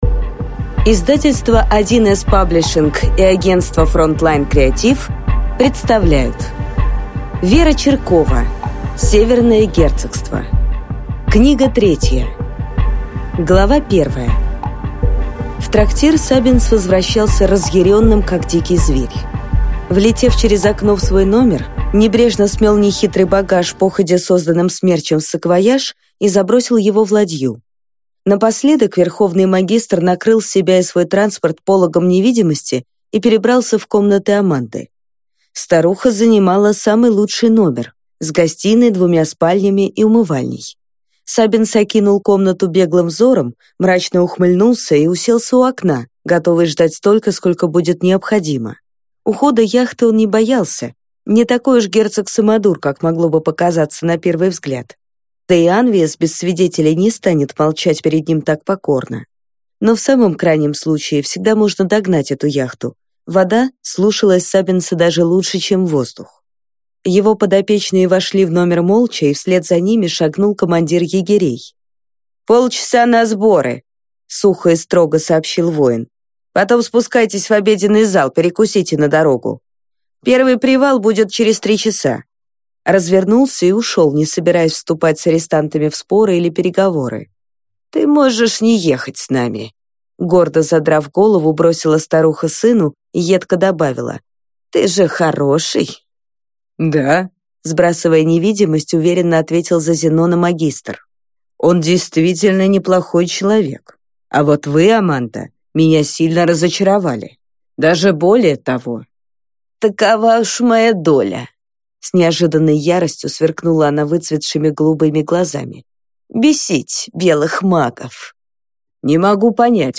Аудиокнига «Сумеречный стрелок 6».